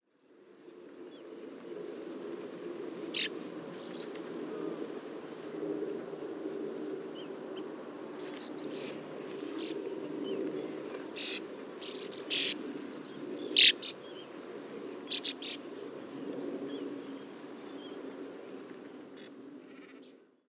PFR07923, 1-00, 130830, Common Black-headed Gull Croicocephalus ridibundus, JUV, AD,
agonistic calls, Seelhausener See, Telinga parabolic reflector